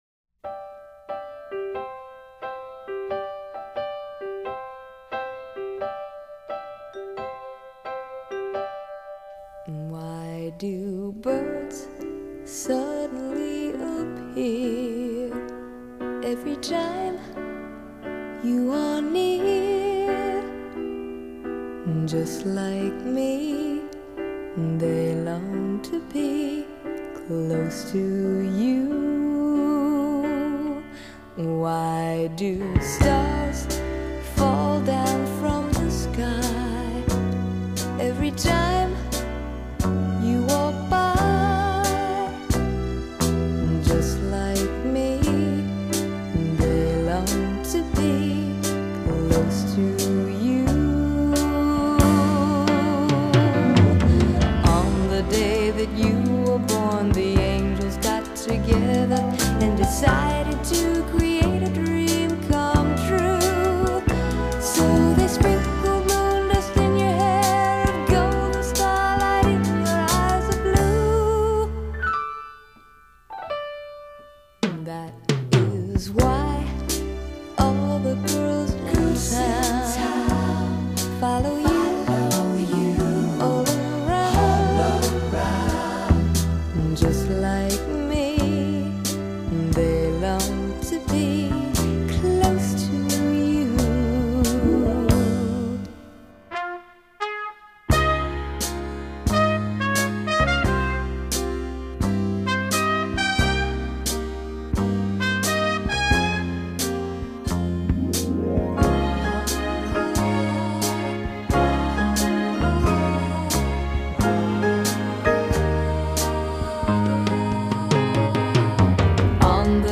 Genre: Pop, Soft Rock, Ballad